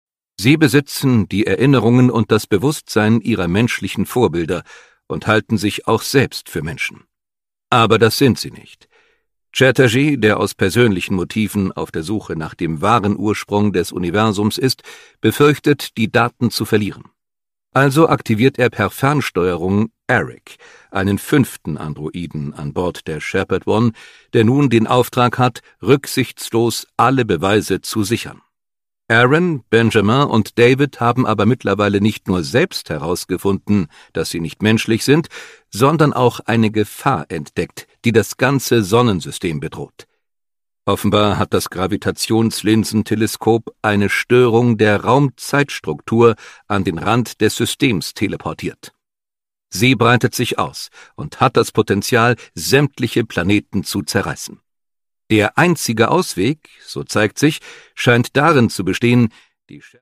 Brandon Q. Morris: Die Antwort - Die Störung, Band 2 (Ungekürzte Lesung)
Produkttyp: Hörbuch-Download